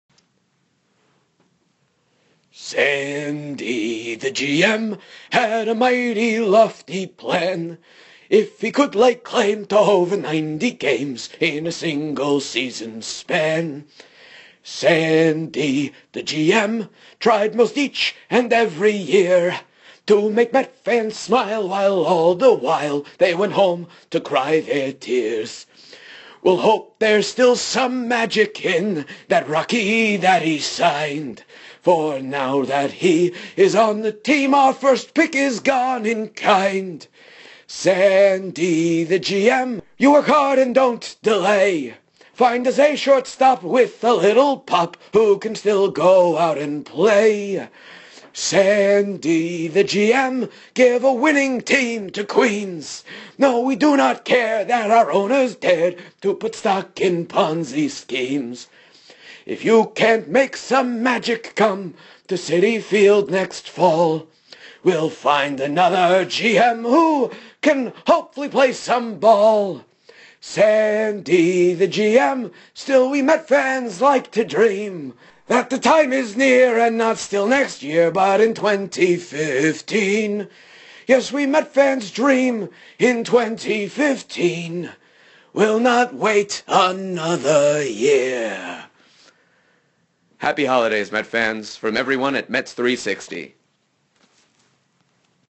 Not sure my Jimmy Durante is spot on, but I try.
That impersonation was spot on as far as I’m concerned.